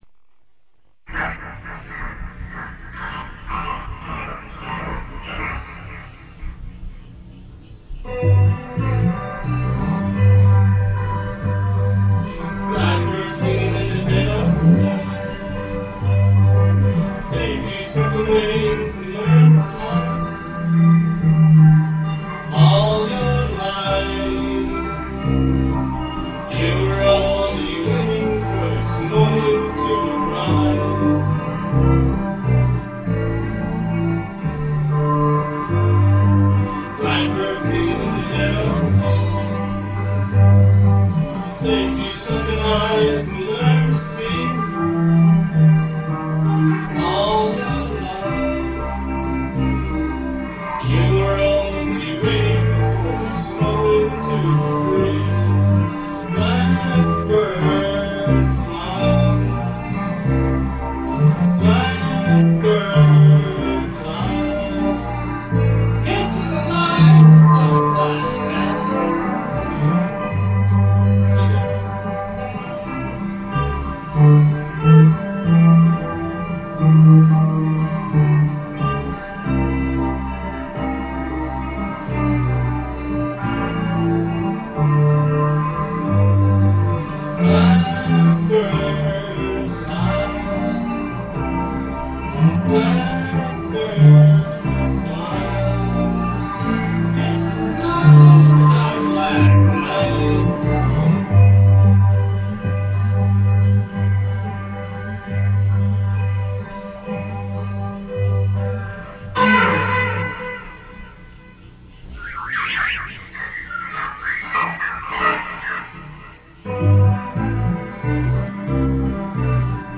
a florida room (garage) band
the recordings are all pretty lo-fi; so what!
i got one! i got one! i got one! (stimpy impersonation)